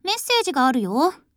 match-start.wav